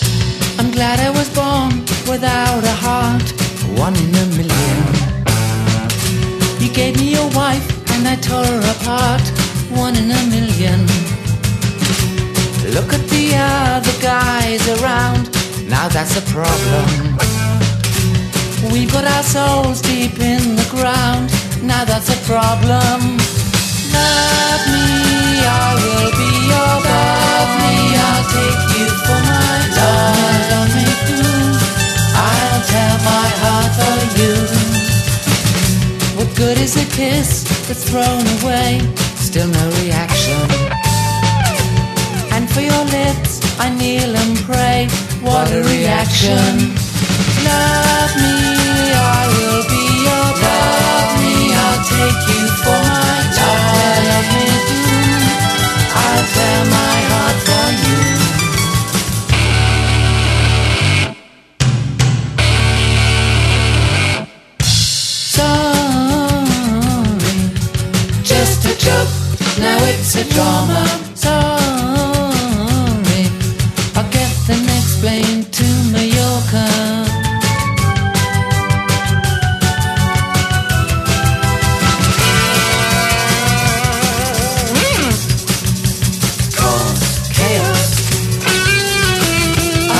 BREAKBEATS / HOUSE (UK)